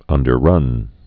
(ŭndər-rŭn)